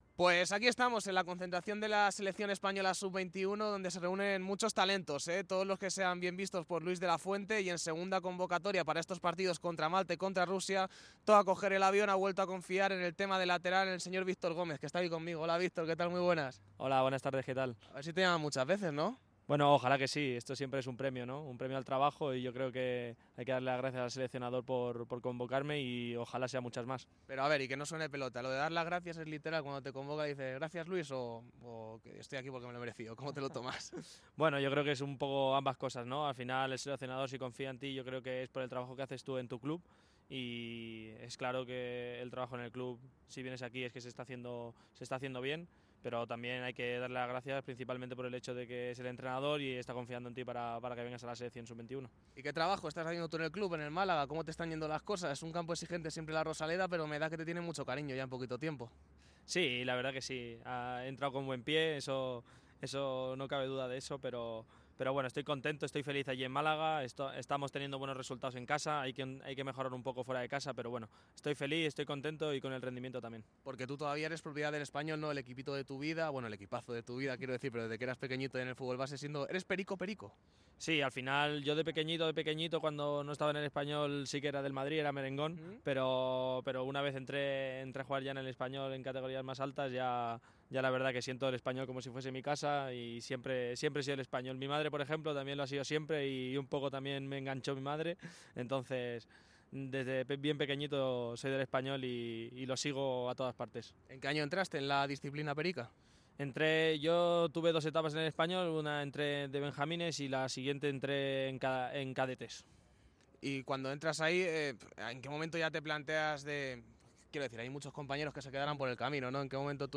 El jugador malaguista habla con MARCA durante su concentración con la sub-21. El lateral catalán tiene claro que Málaga es un puerto de paso.